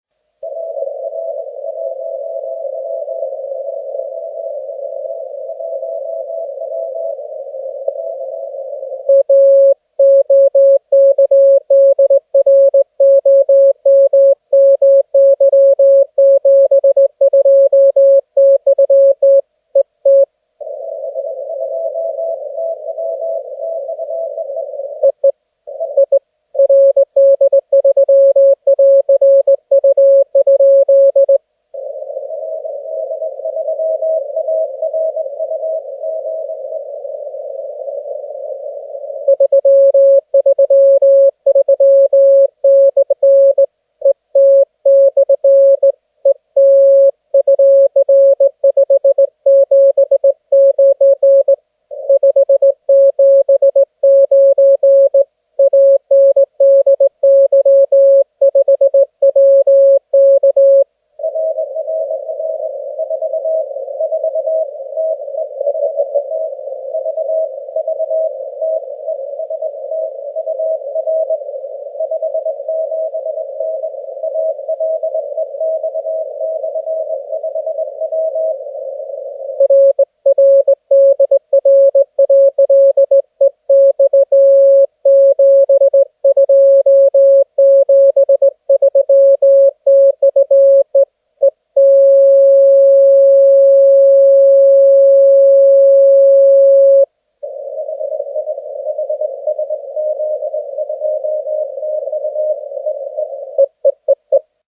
Диапазоны тихие и достаточно чистые, всех было слышно от уровня шума(но разборчиво) до реальных 7 баллов по С-метру.